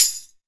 PTAMBOURIN19.wav